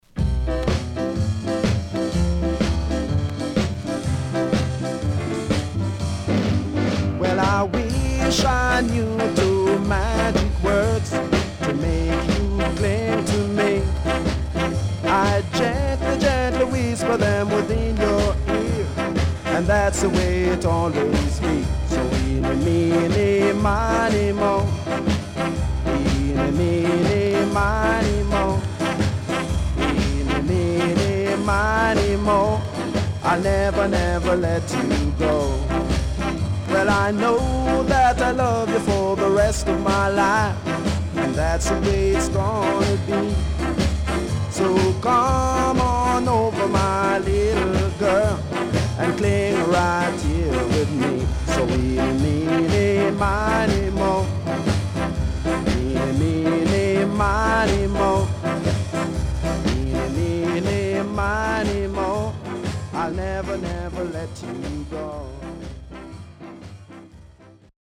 EARLY 60’s
SIDE A:少しチリノイズ入ります。